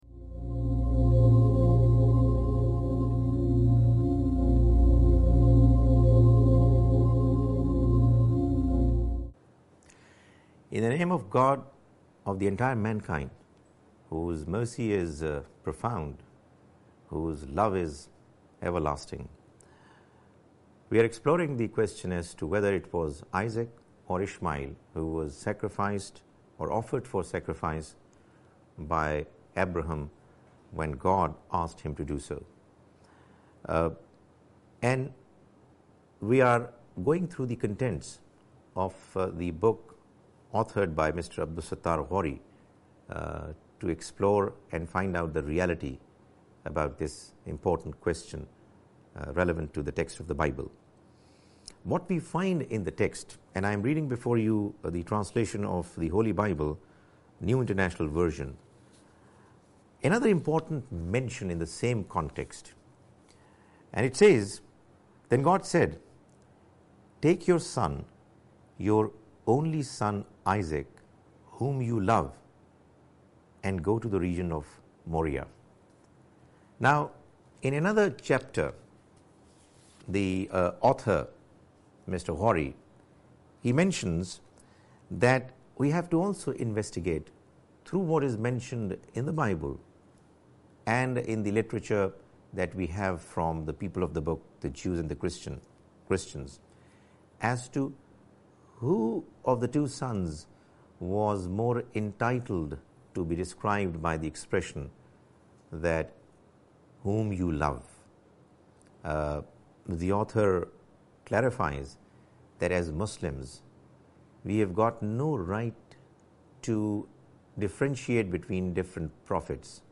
A lecture series